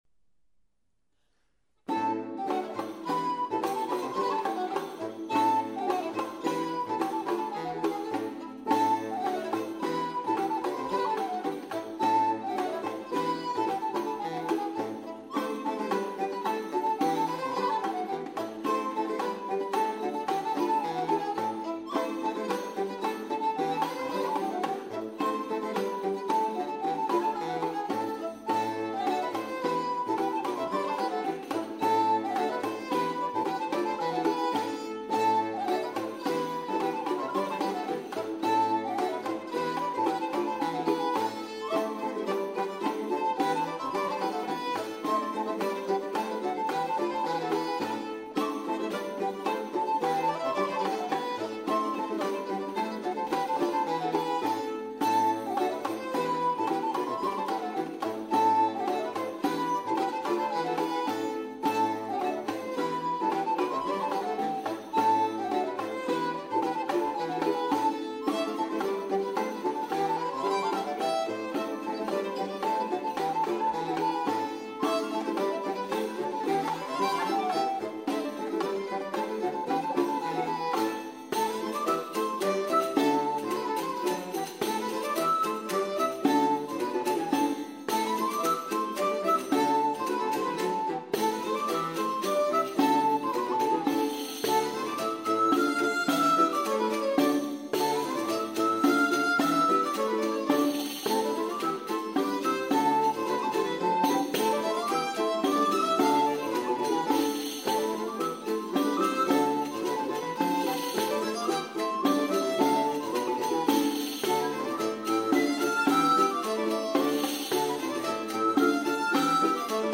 branle simple (3 temps) : 2 pas à gauche, 1 pas à droite
branle simple.mp3